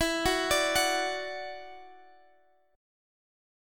Listen to E7sus2 strummed